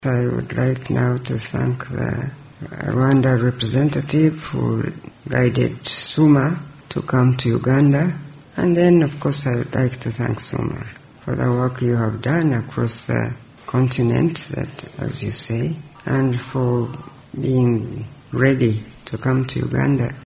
sound-byte-of-Hon.Janet-Museveni-Minister-of-education-thanked-Summa-for-work-done-and-for-coming-to-Uganda.mp3